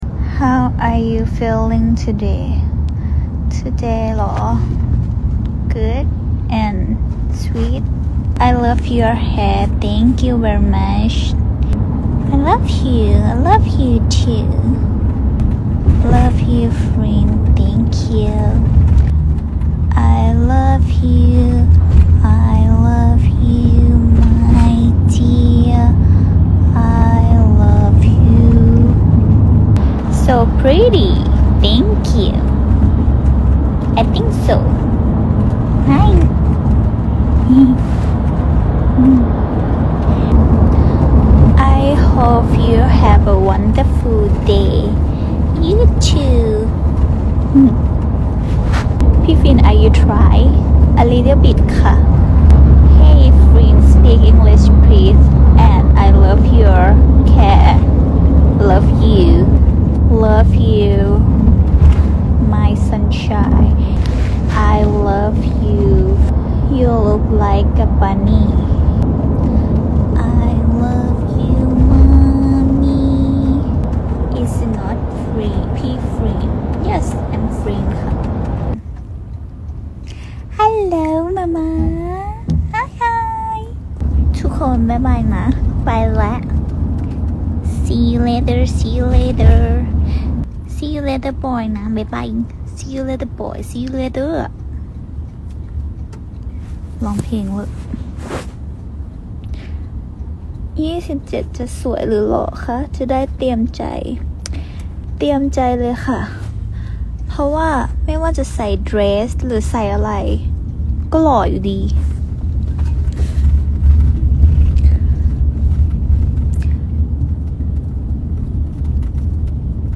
I love her english accent... sound effects free download